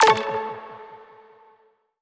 button-back-select.wav